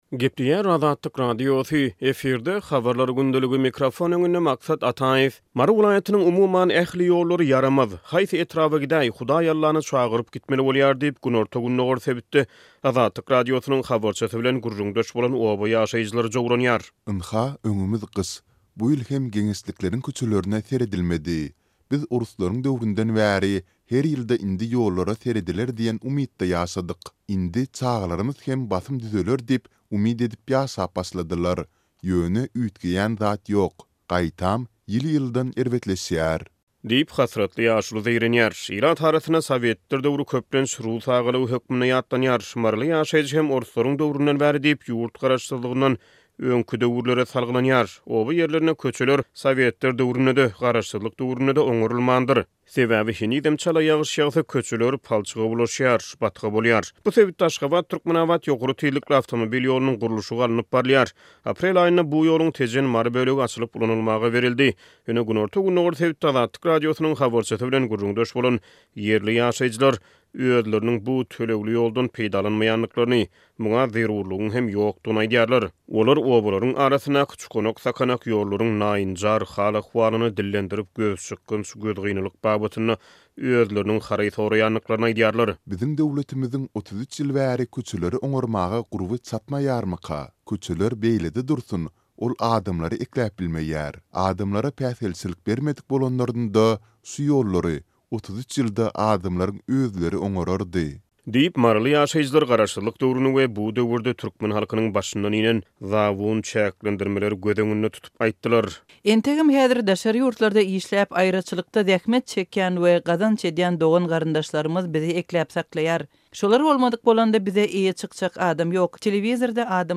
Mary welaýatynyň, umuman, ähli ýollary ýaramaz, haýsy etraba gidäý, Hudaý Allany çagyryp gitmeli bolýar diýip, günorta-gündogar sebitde Azatlyk Radiosynyň habarçysy bilen gürrüňdeş bolan oba ýaşaýjylary jowranýar.